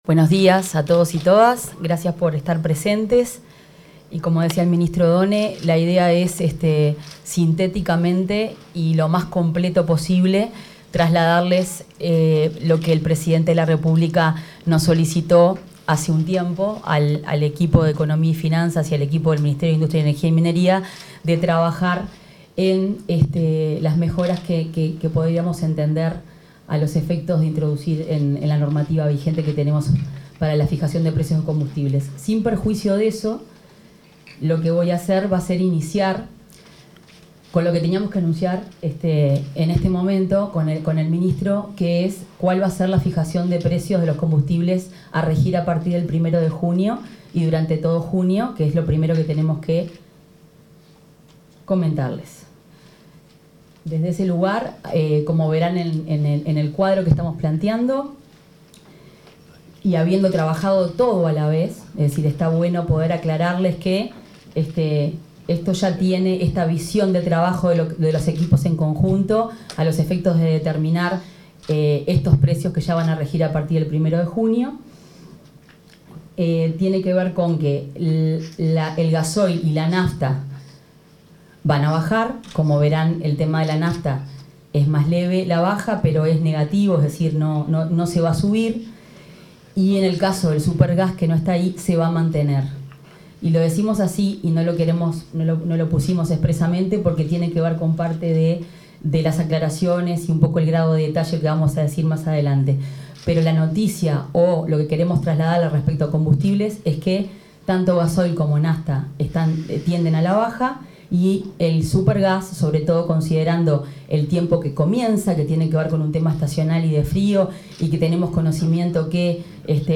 Palabras de autoridades sobre metodología y precios de combustibles 30/05/2025 Compartir Facebook X Copiar enlace WhatsApp LinkedIn Los ministros de Industria, Energía y Minería, Fernanda Cardona, y Economía y Finanzas, Gabriel Oddone, realizaron una conferencia de prensa para informar sobre las tarifas de los combustibles y la nueva metodología de fijación de precios.
oratoria.mp3